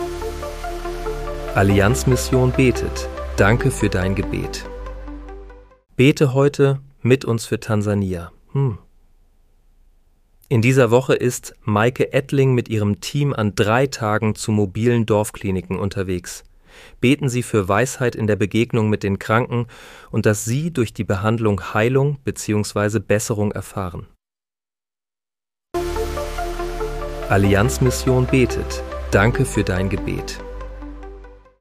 Bete am 24. April 2026 mit uns für Tansania. (KI-generiert mit der